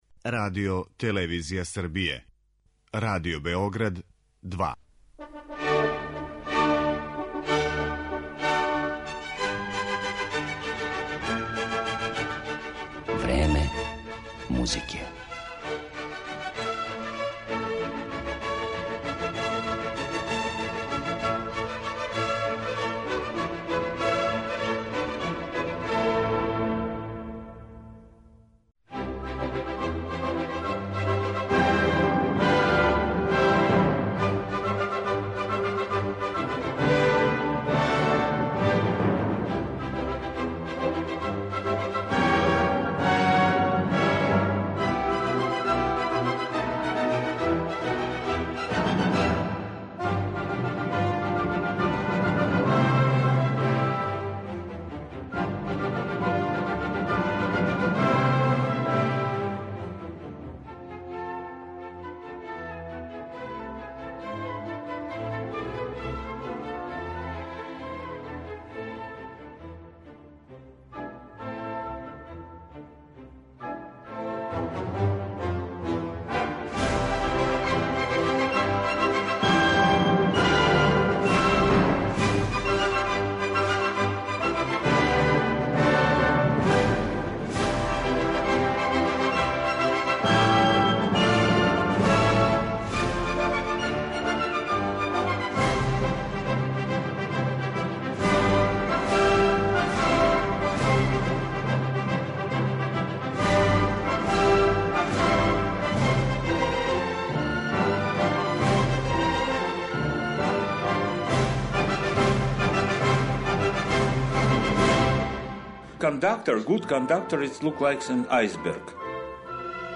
Славном руском диригенту који је недавно поново гостовао у Београду, Михаилу Јуровском, посвећена је данашња емисија. У њој ћете моћи да чујете и интервју са овим врхунским уметником снимљен пред његов концерт одржан у петак 19. маја у сали Коларчеве задужбине, када је поново руководио Београдском филхармонијом.